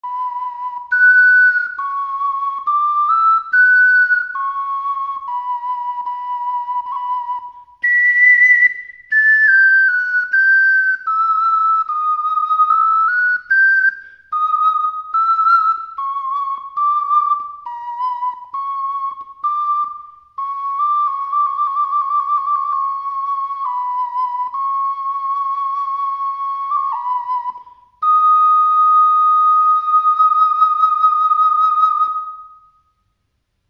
オカリナ
音符をクリックすると管理人のデモ演奏が聴けます。
だいぶリバーブに助けられちゃってます。
中音域のきらびやかな音色が武器。